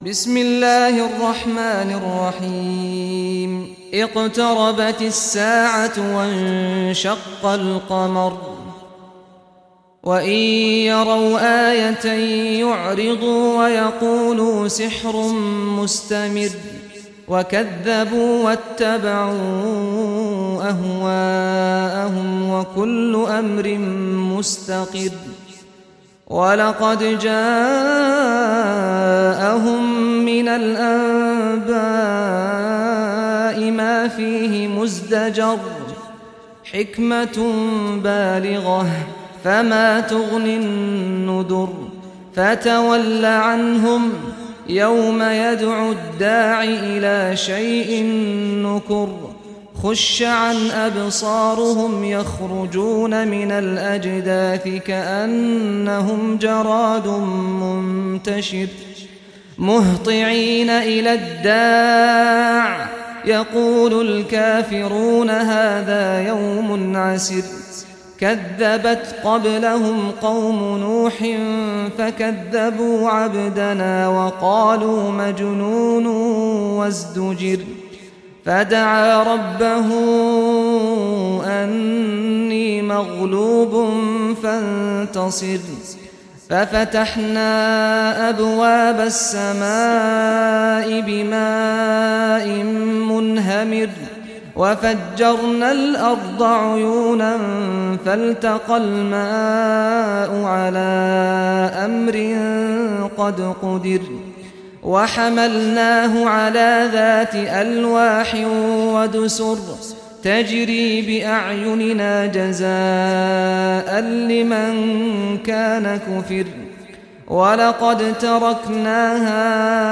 Surah Al-Qamar Recitation by Sheikh Saad al Ghamdi
Surah Al-Qamar, listen or play online mp3 tilawat / recitation in Arabic in the beautiful voice of Sheikh Saad al Ghamdi.